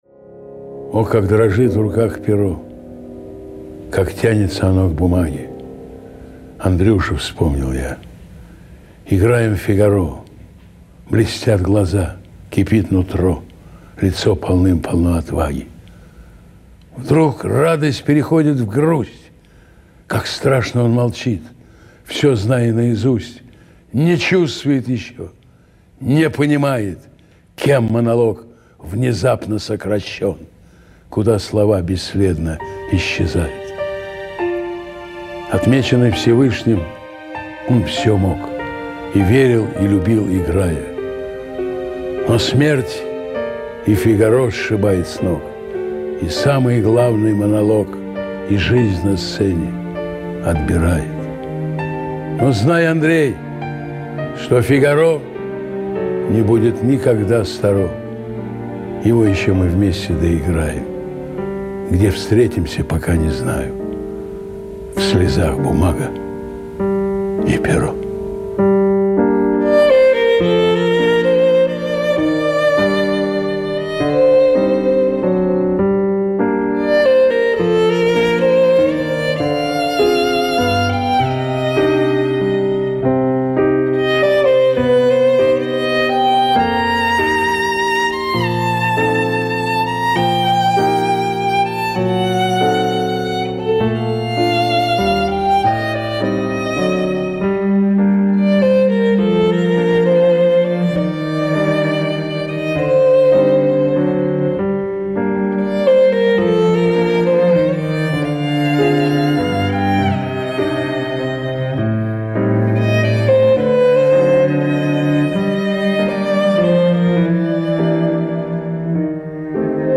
Несколько стихотворений в исполнении автора: